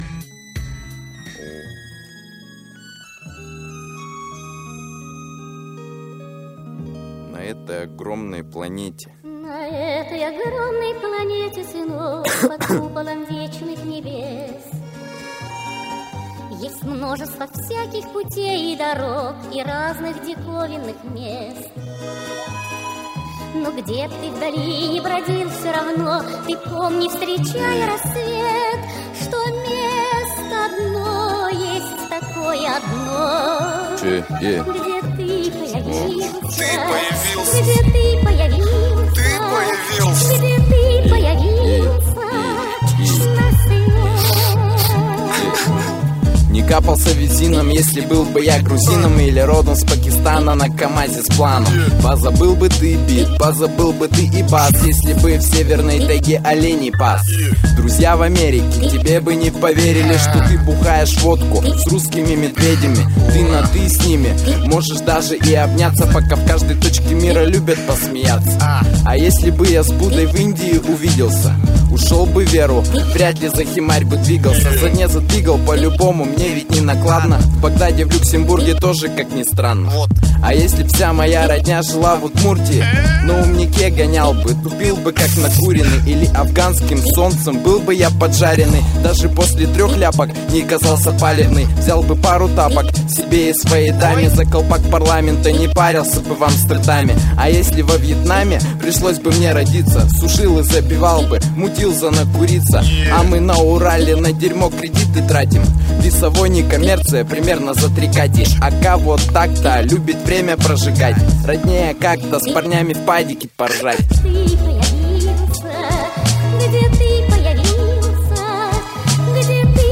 Данная песня находится в музыкальном жанре Русский рэп.
Категория: Русский рэп